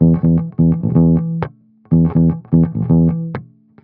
12 Bass Loop E.wav